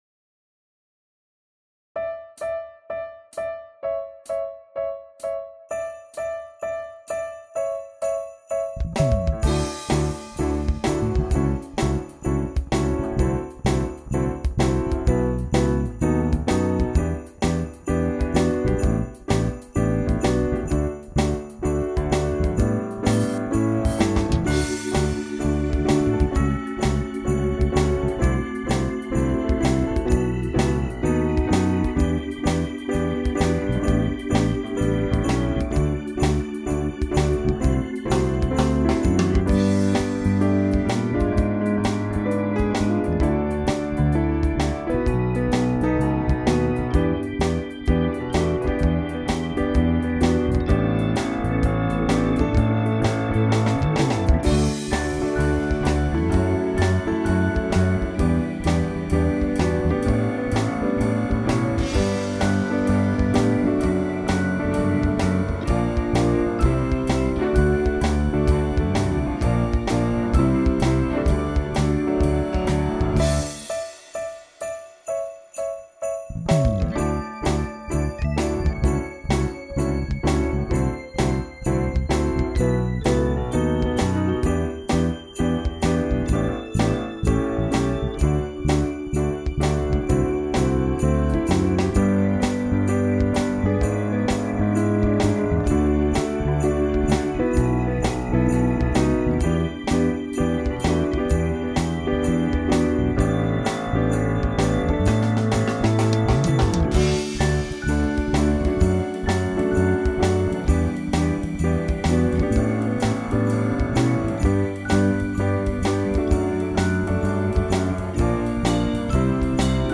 Hola! Us penjo la lletra, la cançó i la base instrumental de la nadala de segon.
Ara-es-Nadal-instrumental.mp3